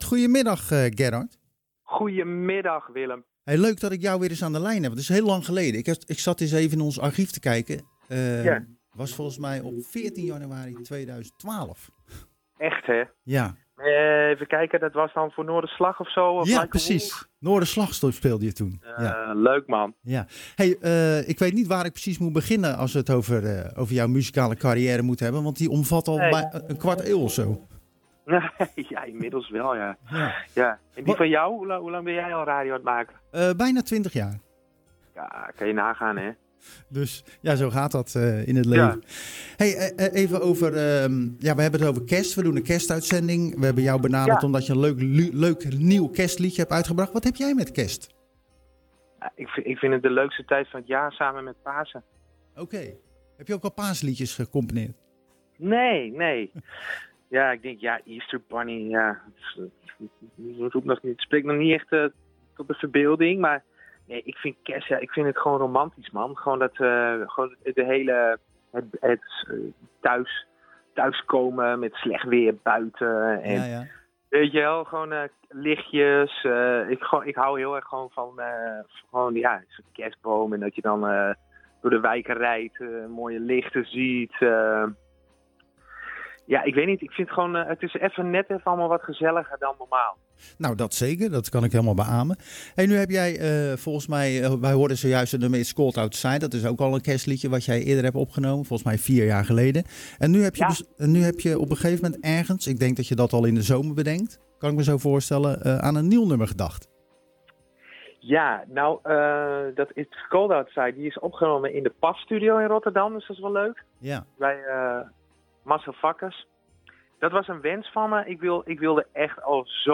Tijdens het programmma Zwaardvis spraken we met muzikant